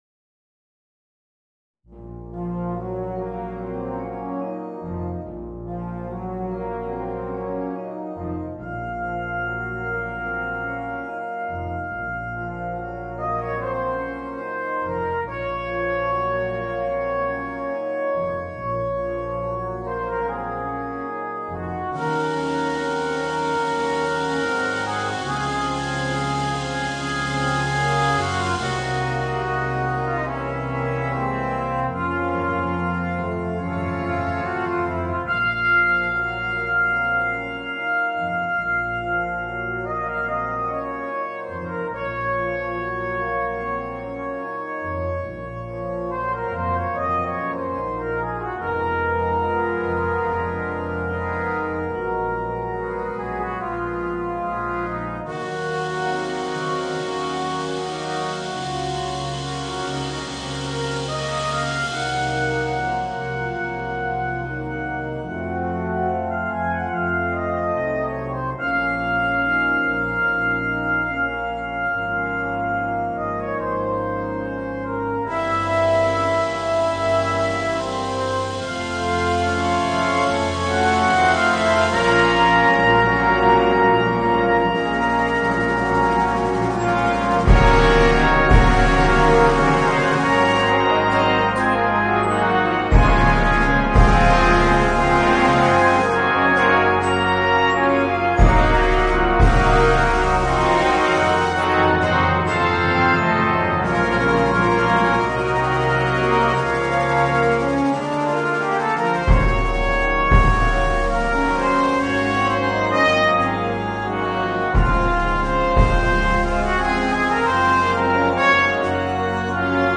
Voicing: Trombone and Brass Band